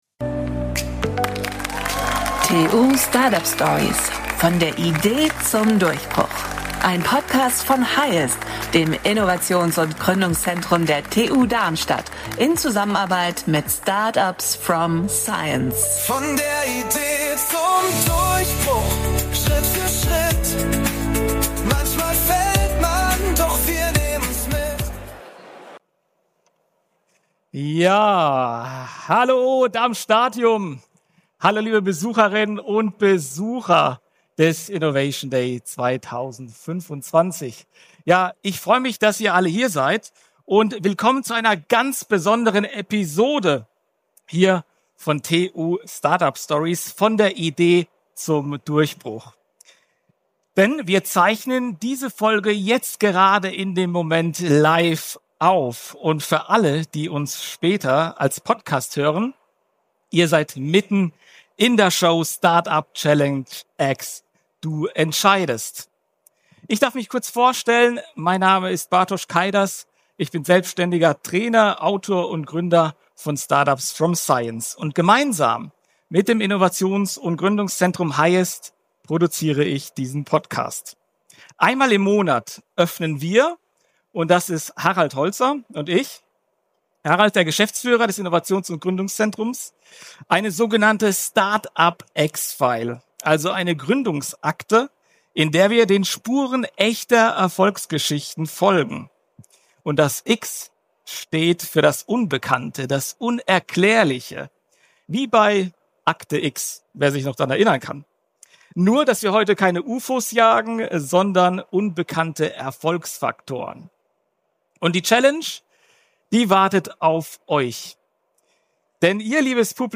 Eine investigative Reise durch vier echte Gründerentscheidungen – mit einem Publikum, das live mitermittelt.